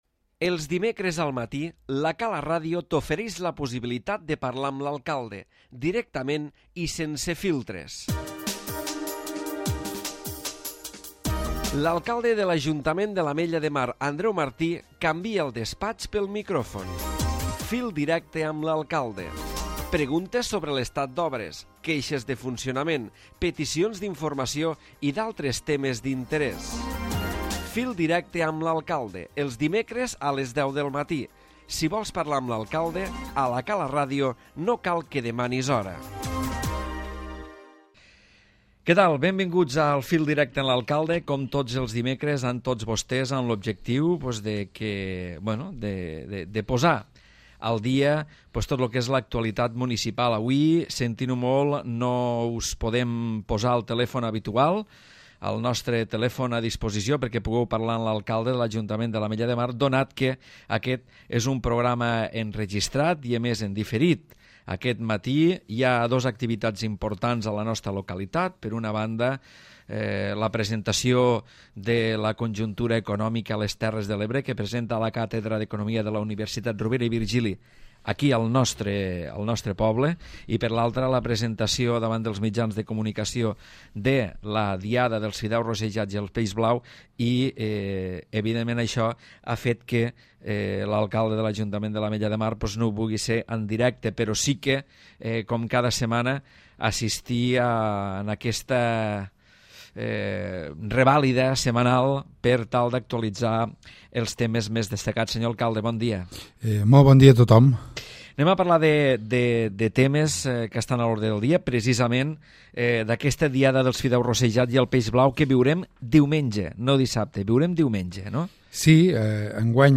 Fil Directe amb l'Alcalde, el programa de participació ciutadana on Andreu Martí, alcalde de l'Ajuntament de l'Ametlla de Mar, respon a les preguntes que per telèfon li fan els oients.